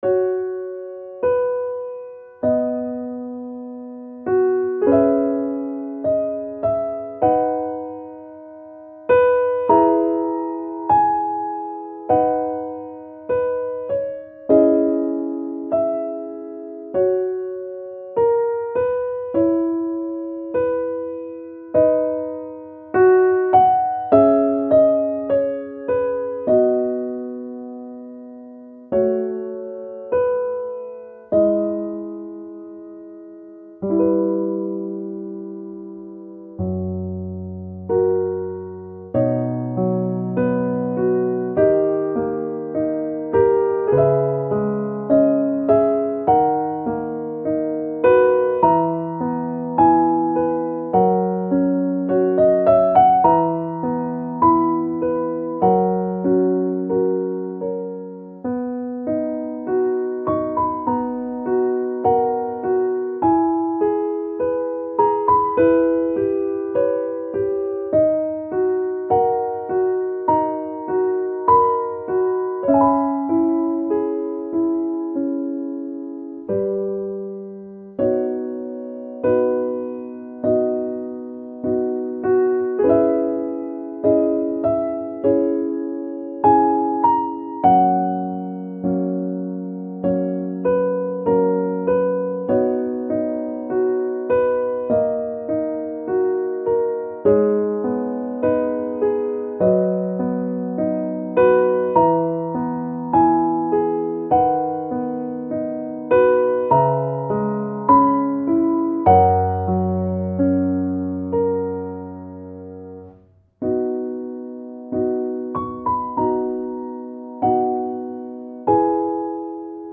• 暗めのしっとりしたピアノ曲のフリー音源を公開しています。
ogg(R) - 孤独 憂鬱 想い